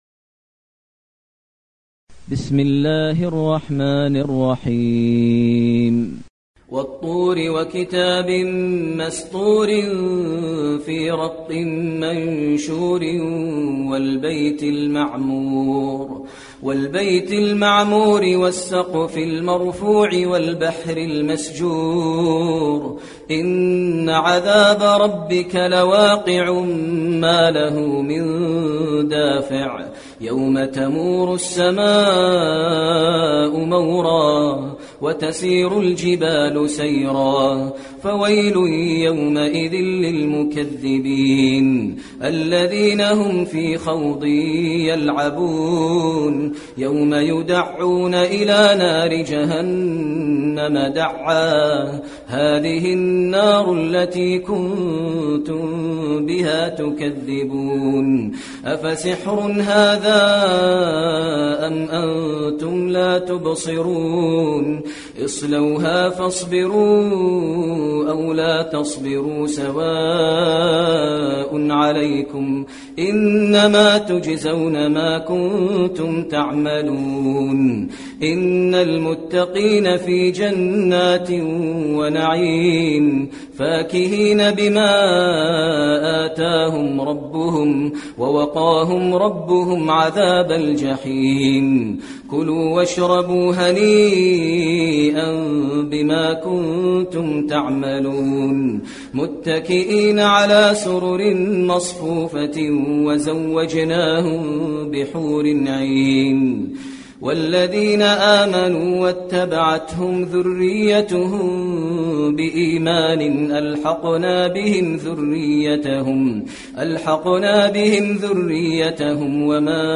المكان: المسجد النبوي الطور